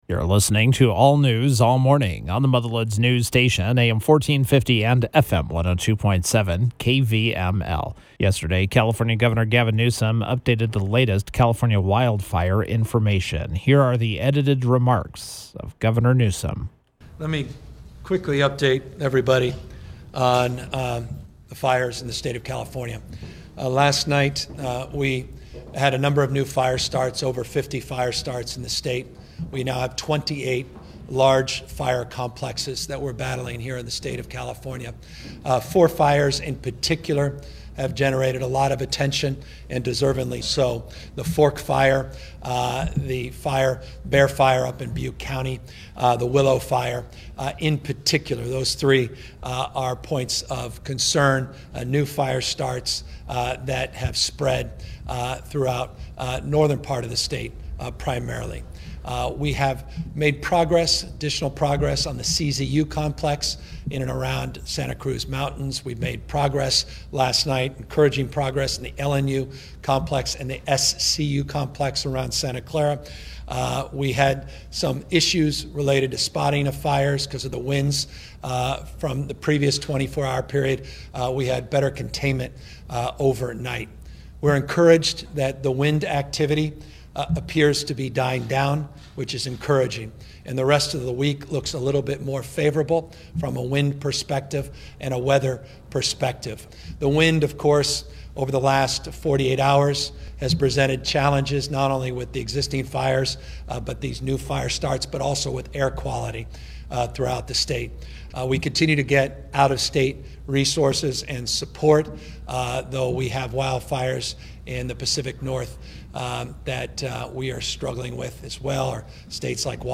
SACRAMENTO — Governor Gavin Newsom delivered an update on the numerous wildfires burning throughout California